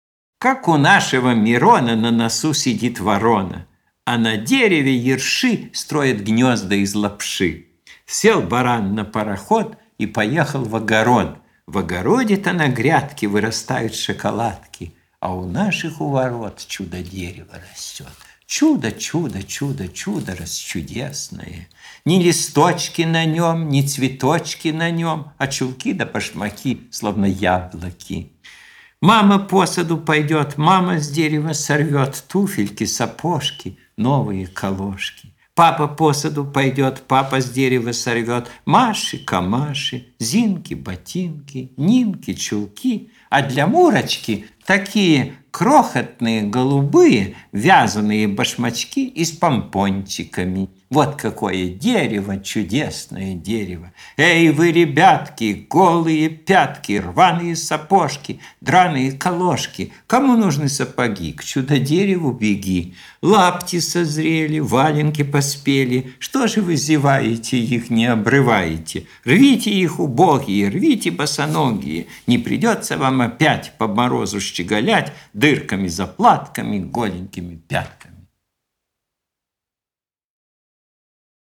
1.Чудо-дерево, читает Чуковский, слушать.